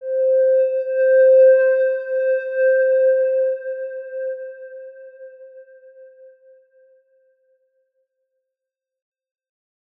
X_Windwistle-C4-pp.wav